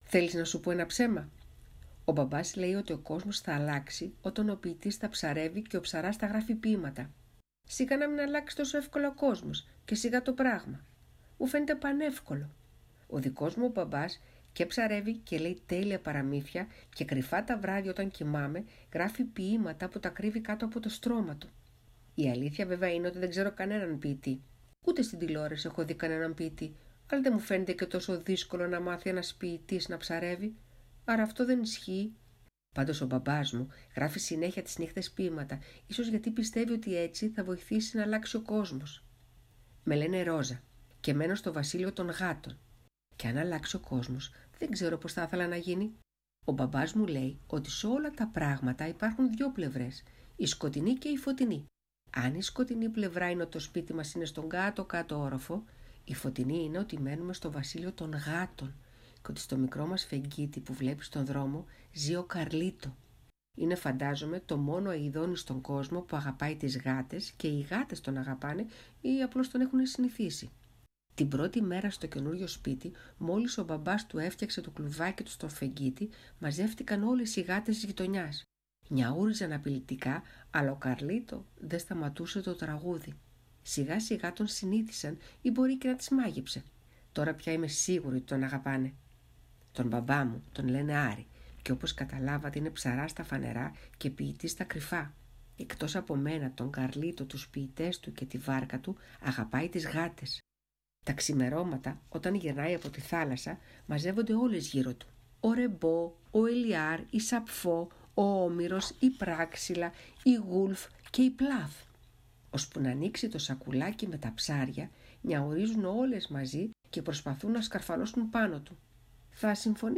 Συγγραφείς διαβάζουν στον Αθήνα 984